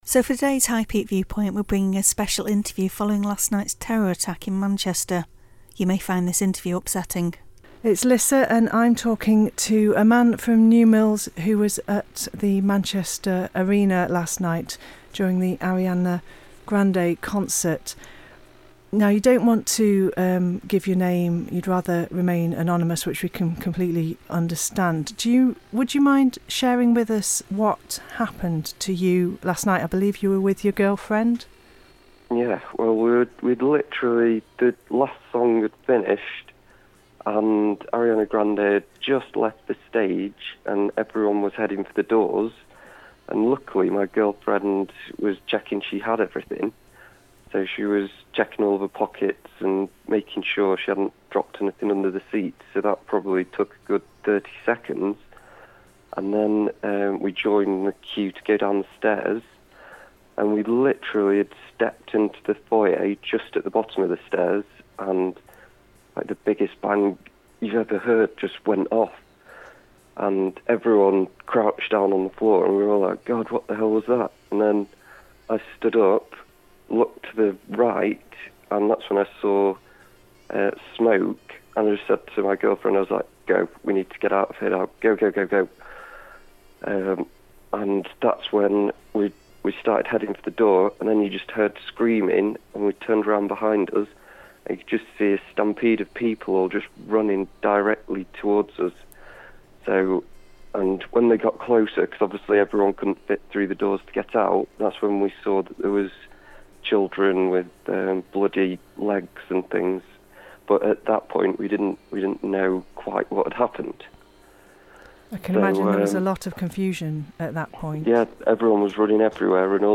A High Peak man who was caught up in the terror attack in Manchester on Monday night has told us what he saw.